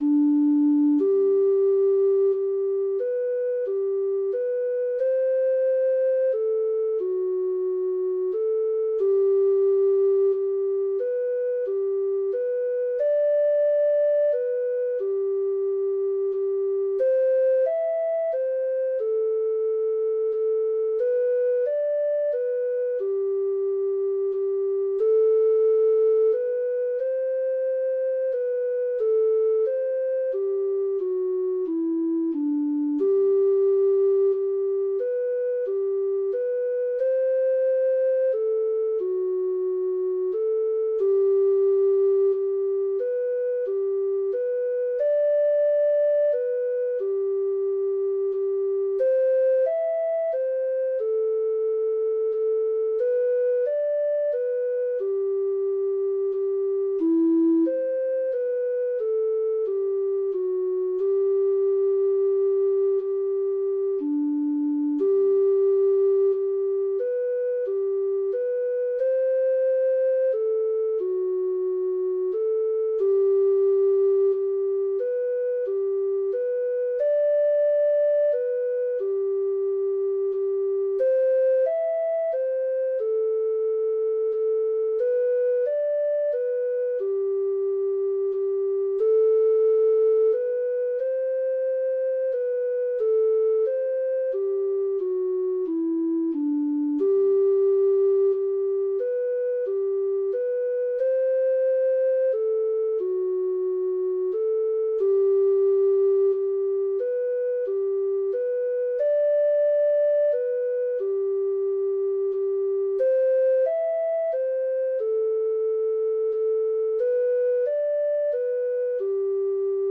Ireland    longdance
Irish Longdances